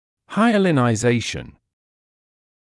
[ˌhaɪələnaɪ’zeɪʃn][ˌхайэлэнай’зэйшн]гиалинизация